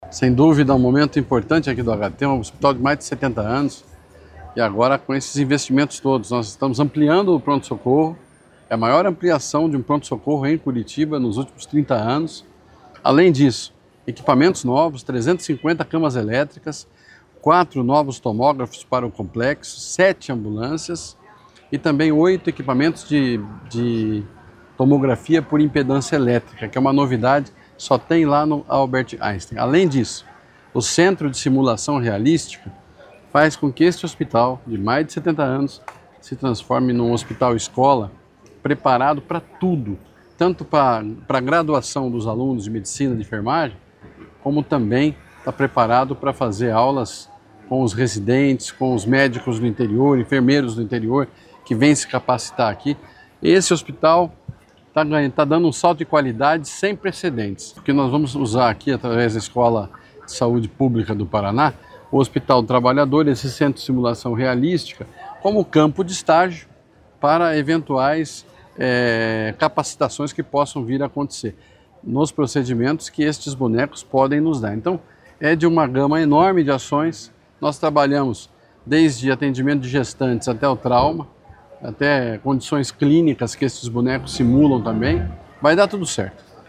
Sonora do secretário da Saúde, Beto Preto, sobre a ampliação do Pronto-Socorro do Hospital do Trabalhador